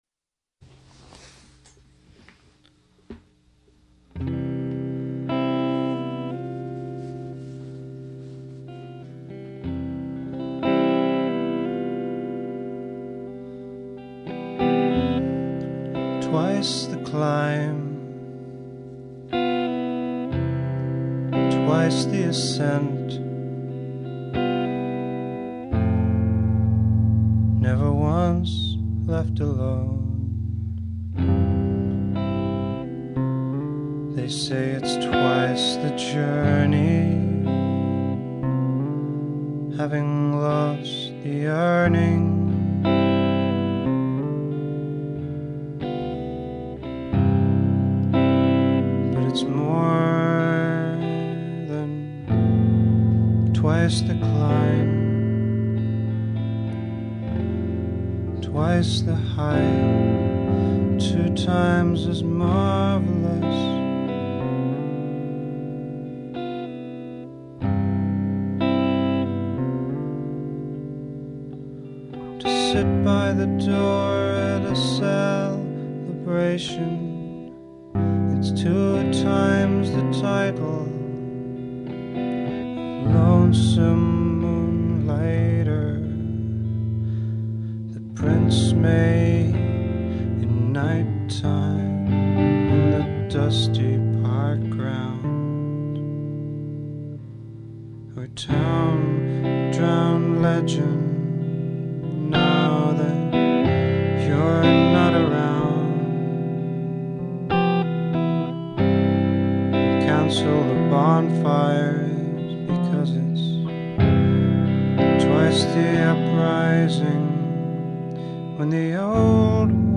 handmade (old sketches, demos, errors)